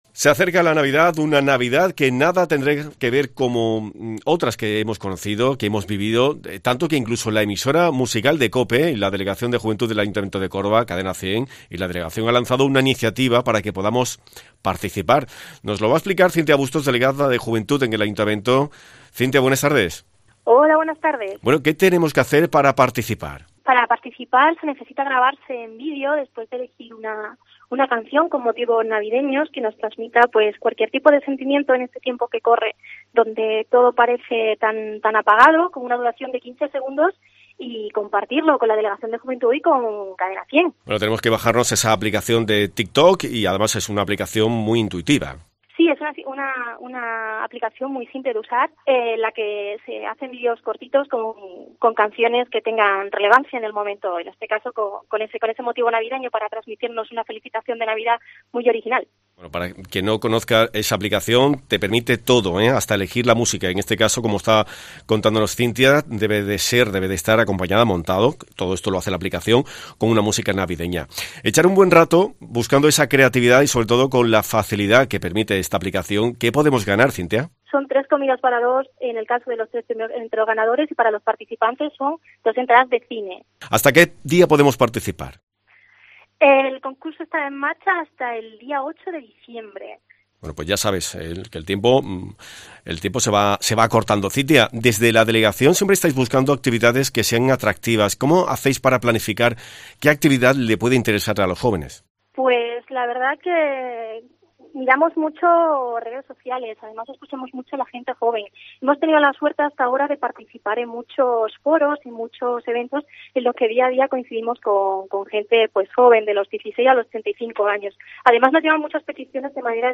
Cintia Bustos, concejala de Juventud del Ayuntamiento, ha explicado este lunes en COPE los pasos a seguir para concursar: “Grabarse en vídeo después de emitir una canción con motivos navideños que transmita cualquier sentimiento en estos tiempos en los que todo parece tan apagado con una duración de 15 segundos y compartirlo en Tik-Tok citando las cuentas de Delegación de Juventud y Cadena 100”.